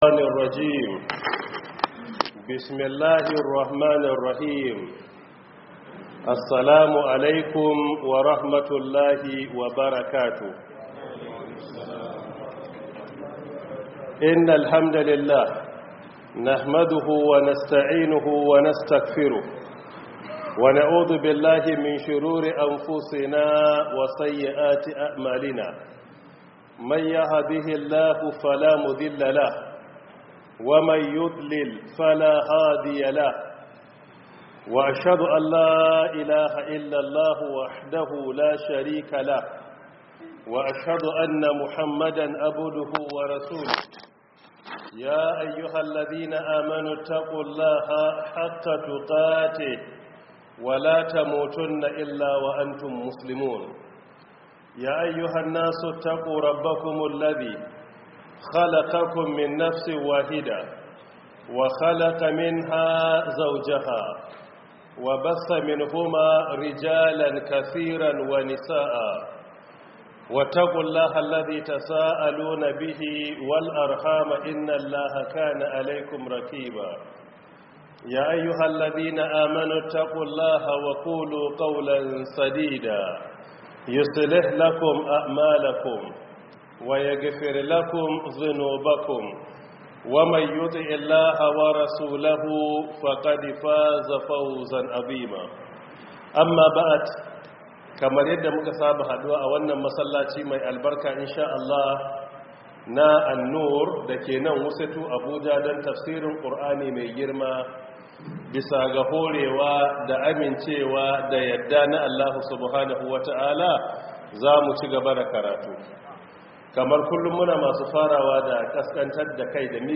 Download Audio From Book: 1447/2026 Ramadan Tafsir